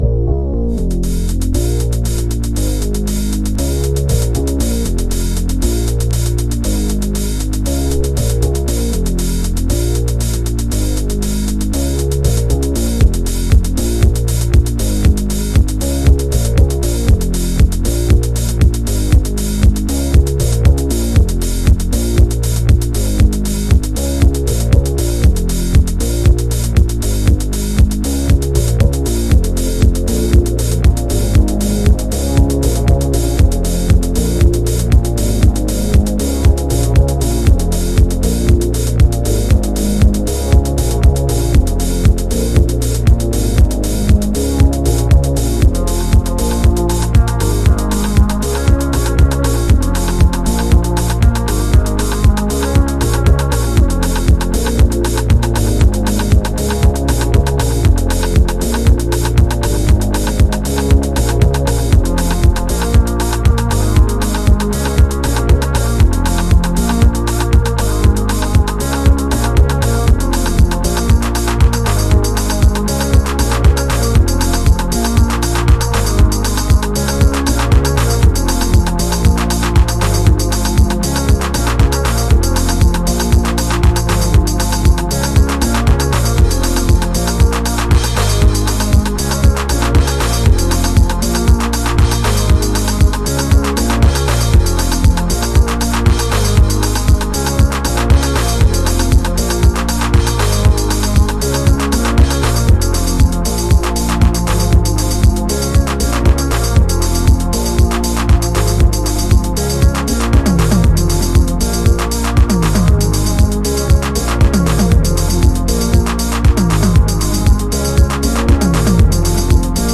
House / Techno
Instrumental